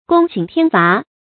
龔行天罰 注音： ㄍㄨㄙ ㄒㄧㄥˊ ㄊㄧㄢ ㄈㄚˊ 讀音讀法： 意思解釋： 奉天之命進行懲罰 出處典故： 東漢 班固《漢書 敘傳下》：「皇矣漢祖， 龔行天罰 ，赫赫明明。」